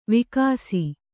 ♪ vidāsi